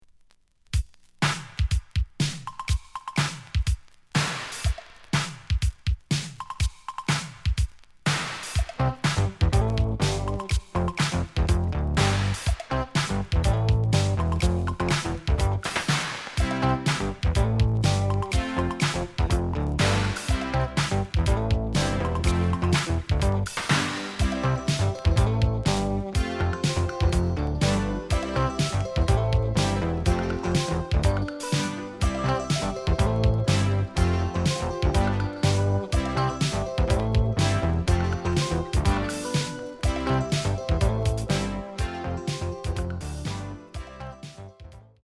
(Instrumental - Short Version)
The audio sample is recorded from the actual item.
●Format: 7 inch
●Genre: Disco